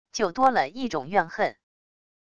就多了一种怨恨wav音频生成系统WAV Audio Player